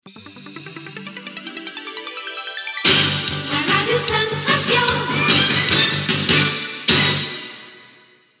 Indicatiu de la radiofórmula